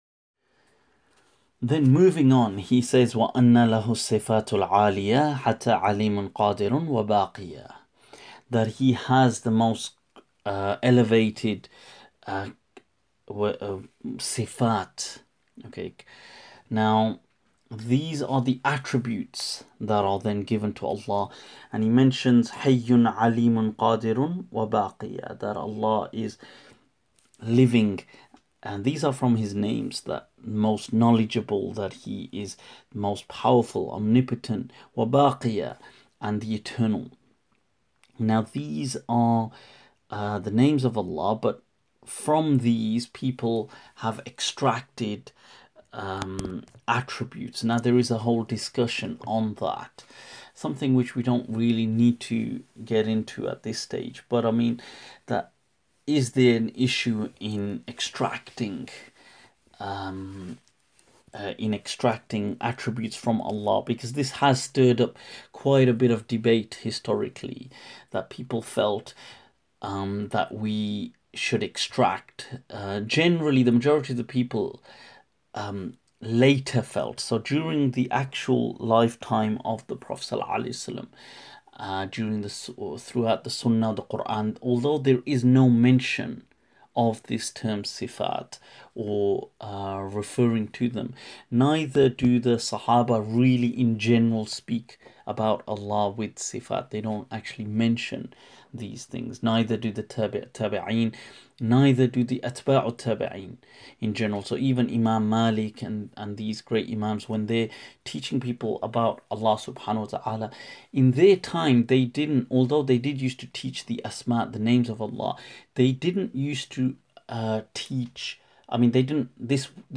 I will be releasing only a few lines of the poetry regularly (daily if possible) along with an audio commentary spanning anything between 5-10 minutes...to help any who have kindled within them a flame no matter how bright or dim yet burning for the Legacy of our Deen.